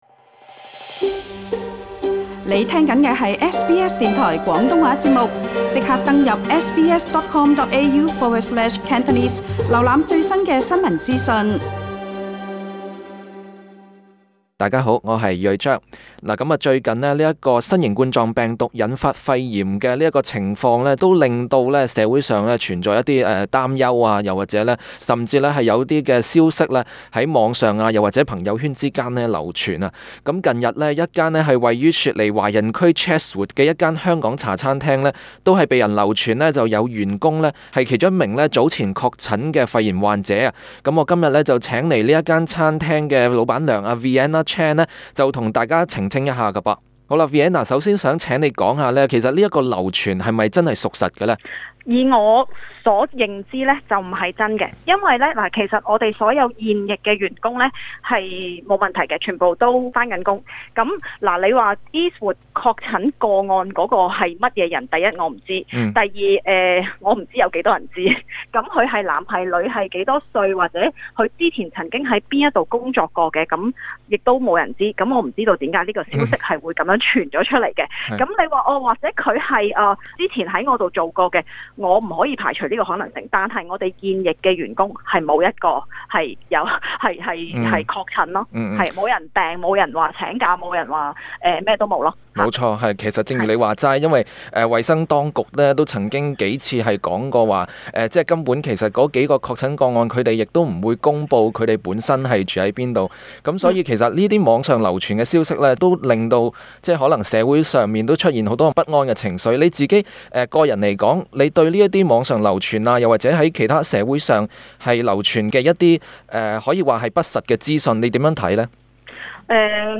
在接受 SBS 廣東話節目訪問時表示